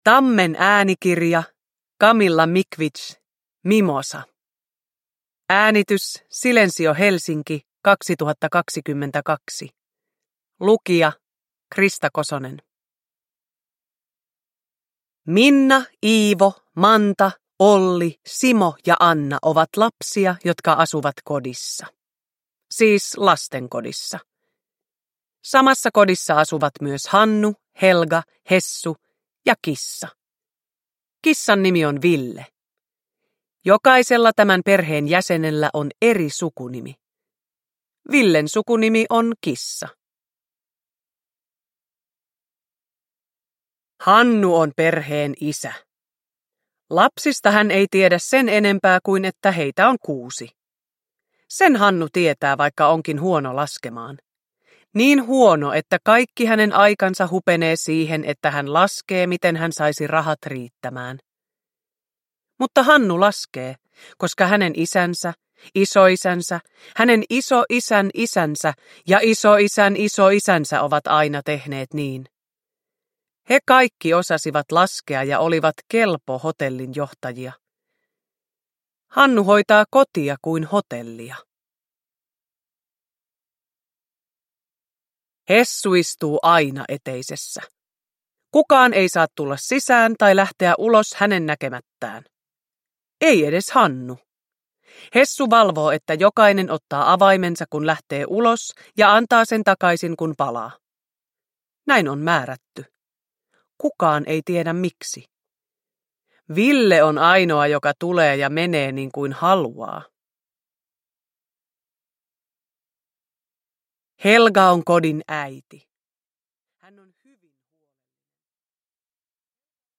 Mimosa – Ljudbok – Laddas ner
Uppläsare: Krista Kosonen